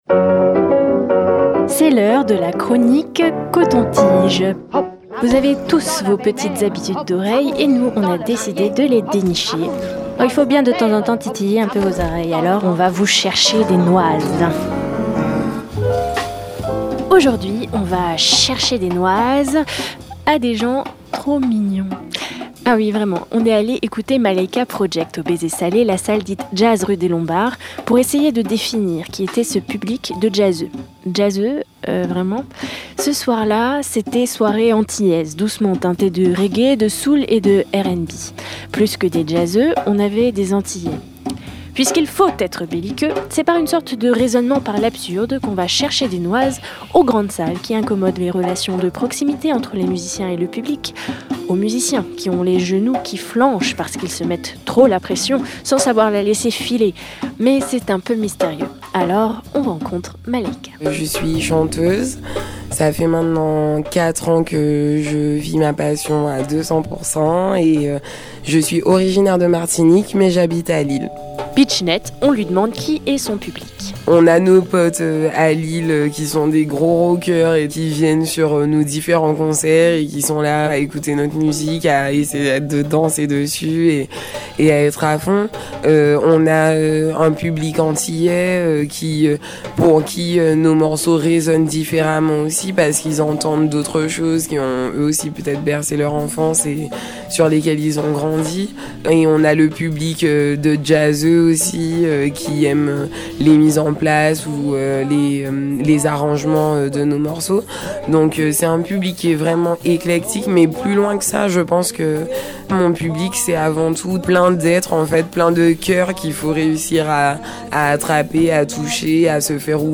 C’était au Baiser Salé, une salle de réputation jazz, mais qui est sûrement aussi un peu autre chose.
C’était des gens qui chantaient, qui dansaient, et qui  se lançaient des vannes. Les musiciens et la chanteuse?